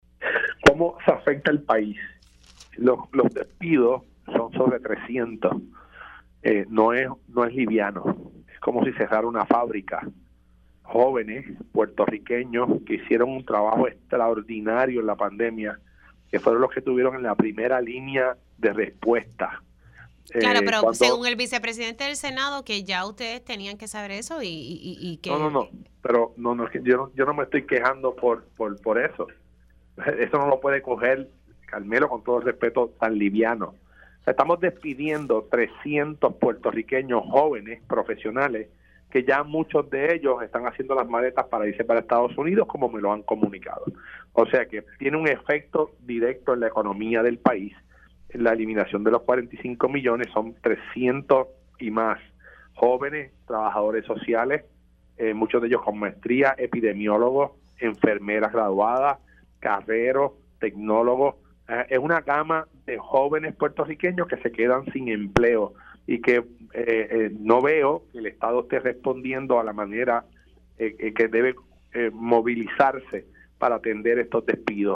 El alcalde de Coamo, Juan Carlos ‘Tato’ García Padilla informó en Pega’os en la Mañana que la pérdida de 45 millones de dólares en fondos federales resultará en el despido de 300 empleados de salud.